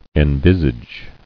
[en·vis·age]